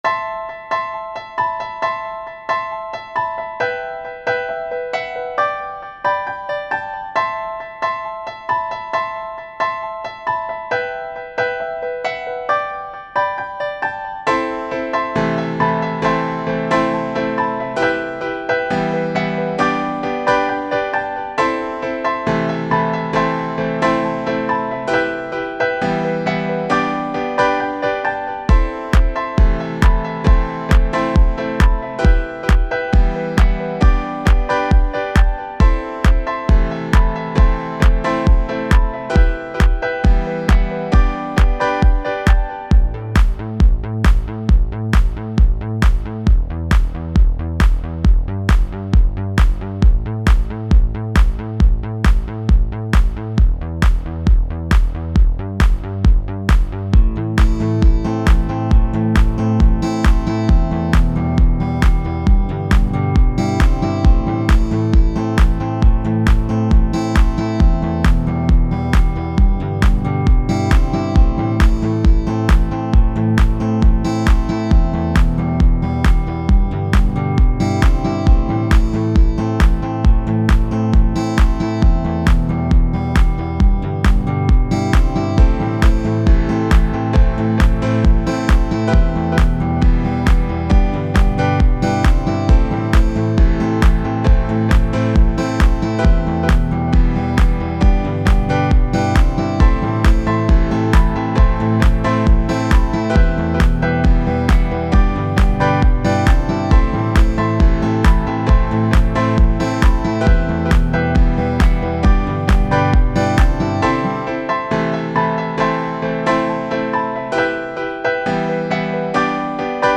royalty free music
04:08 Techno 5.8 MB